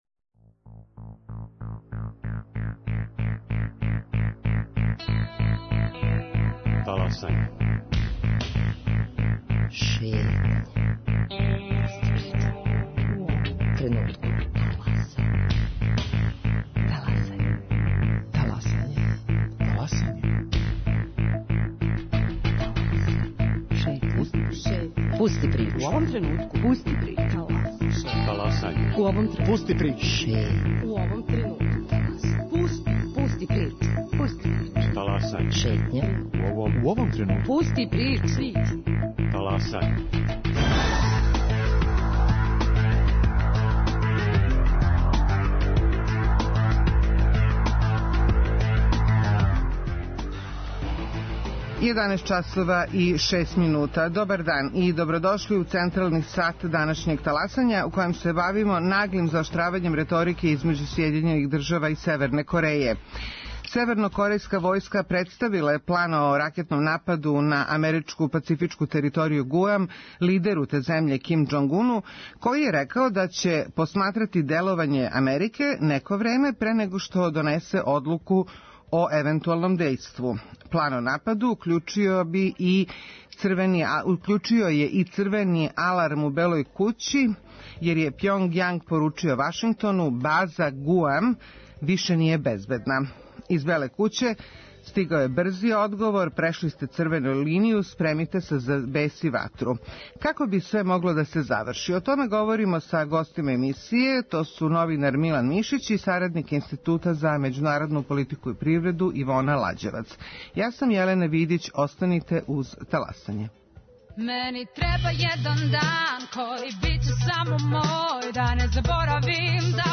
Гости: новинар